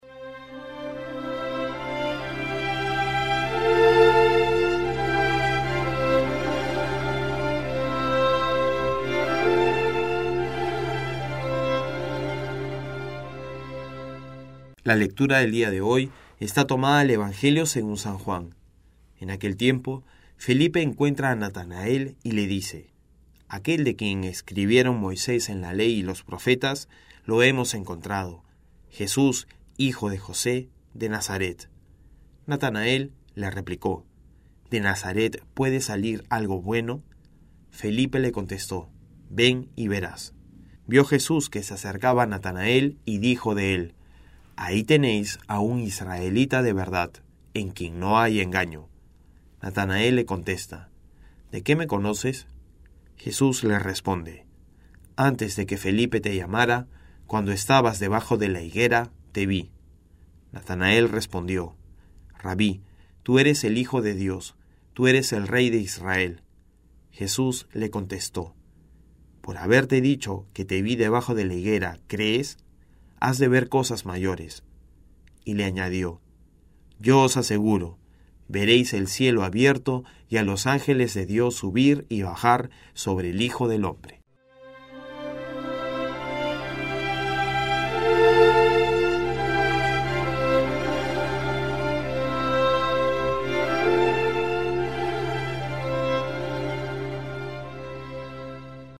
Evangelio en Audio